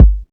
kits/OZ/Kicks/K_Two.wav at main